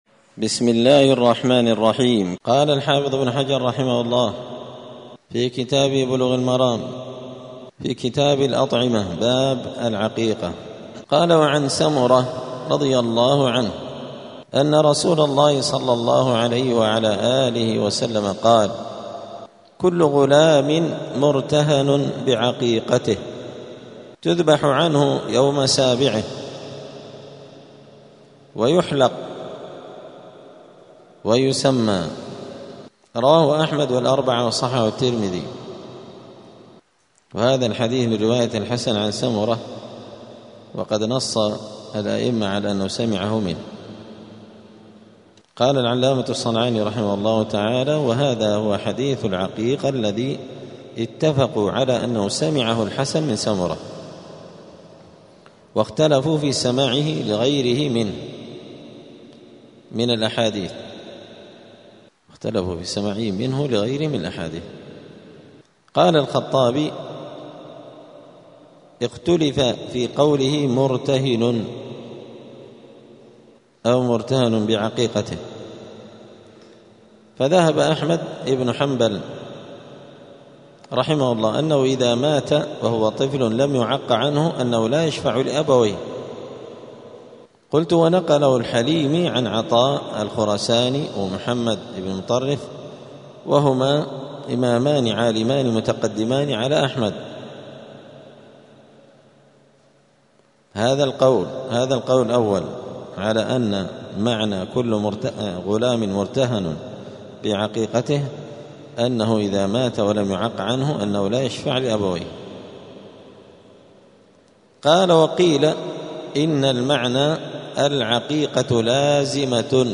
*الدرس الثلاثون (30) {باب العقيقة اﺭﺗﻬﺎﻥ اﻟﻐﻼﻡ ﺑﻌﻘﻴﻘﺘﻪ}*
دار الحديث السلفية بمسجد الفرقان قشن المهرة اليمن